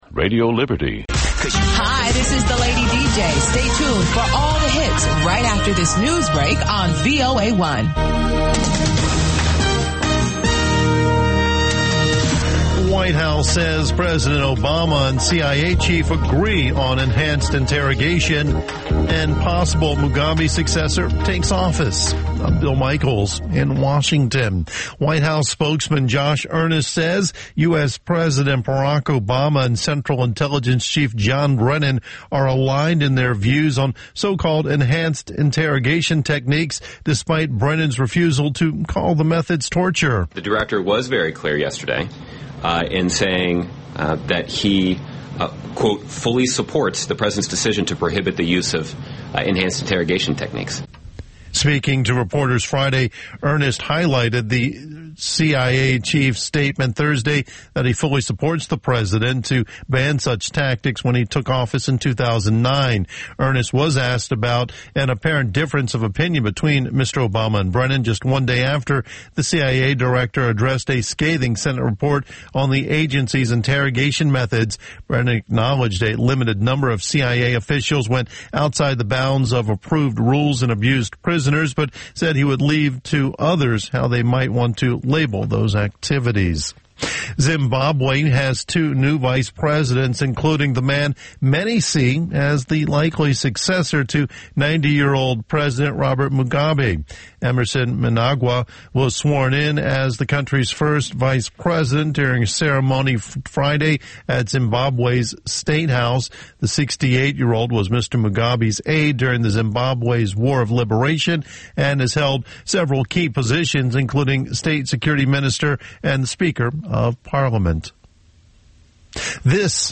"ამერიკის ხმის" ახალი ამბები (ინგლისურად) + VOA Music Mix